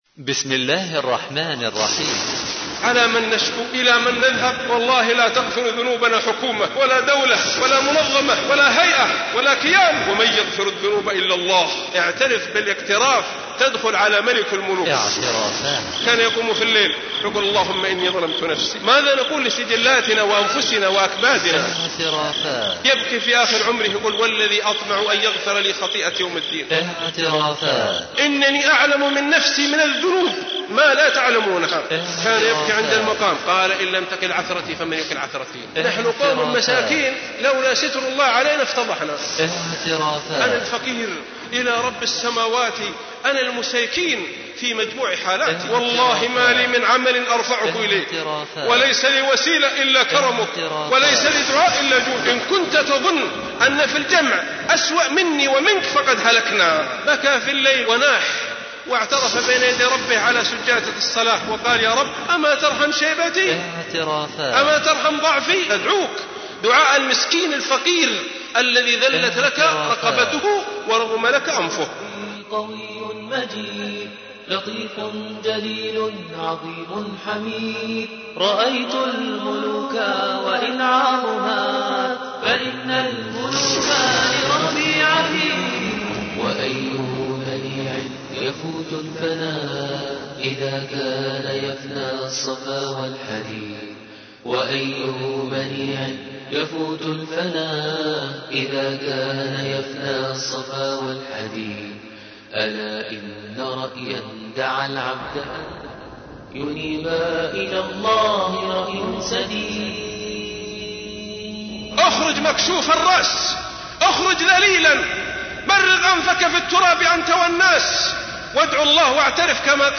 السلام عليكم ورحمة الله وبركاته نبذة عن المحاضرة : اعترف لربك بذنبك فإن الإعتراف من أسباب غفران الذنوب ..
للشيخ: عائض القرني حجم الملفات : ( RM : 4.86 MBytes ) - ( MP3 : 13.58 MBytes ) لحفظ المحاضرة: MP3 RM